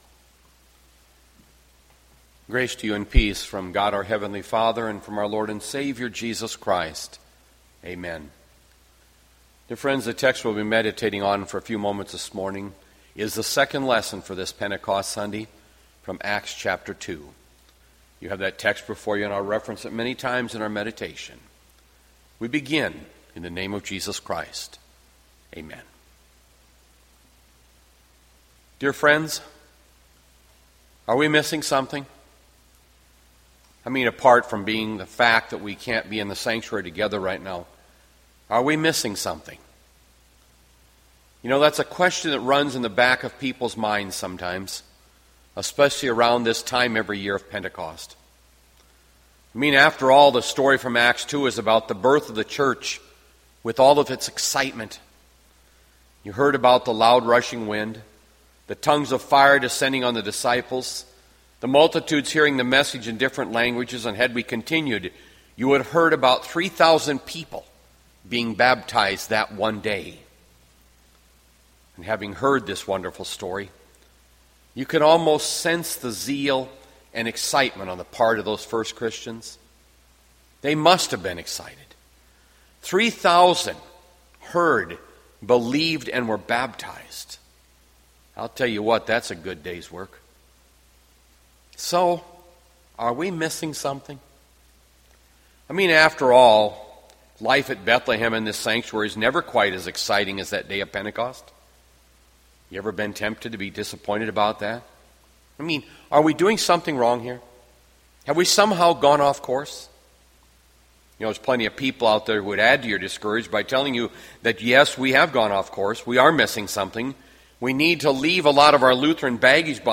Bethlehem Lutheran Church, Mason City, Iowa - Sermon Archive May 31, 2020